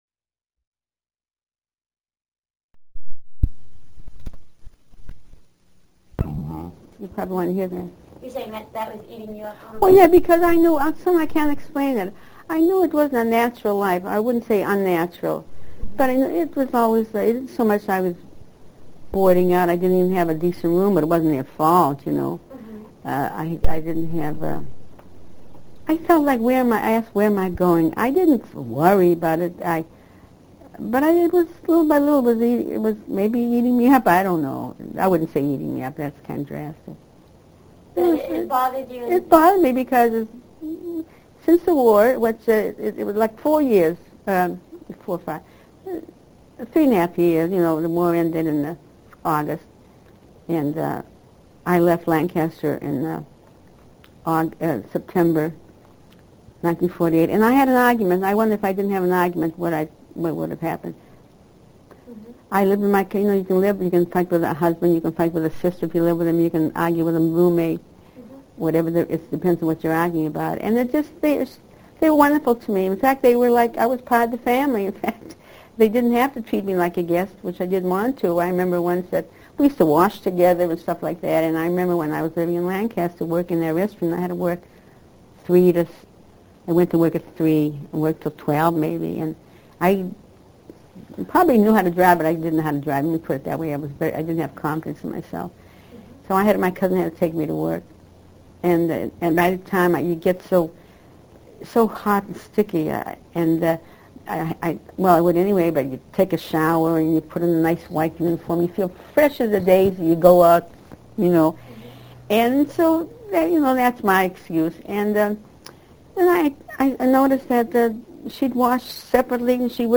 audio interview #3 of 4